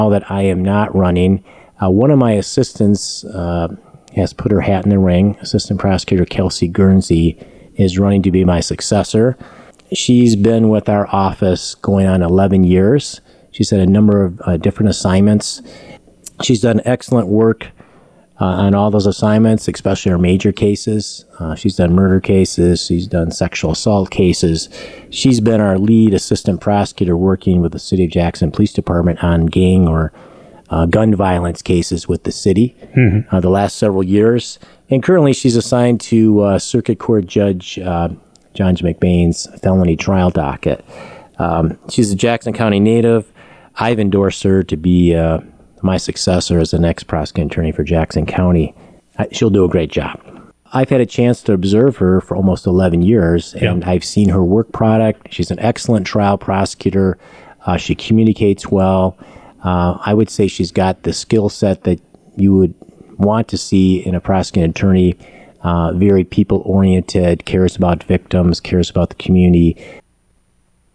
Jarzynka was live on WKHM Wednesday: